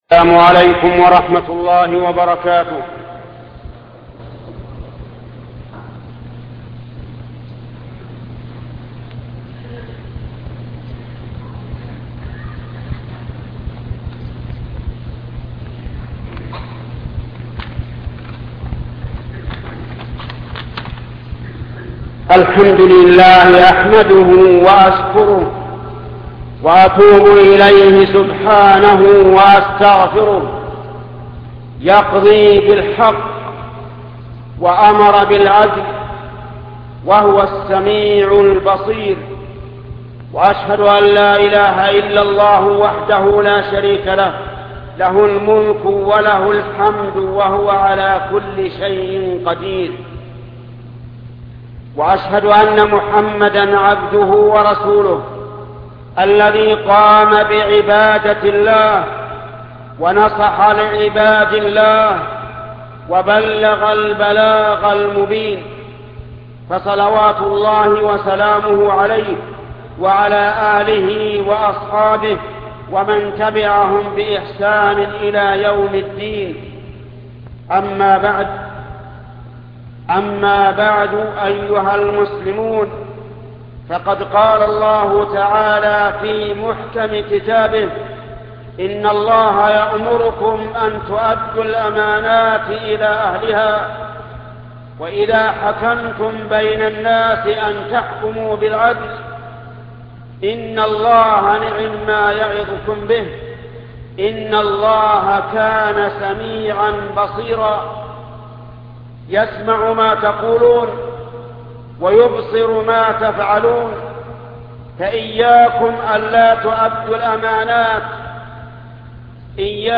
خطبة أداء الأمانة بين المدرس والطالب عند الاختبار الشيخ محمد بن صالح العثيمين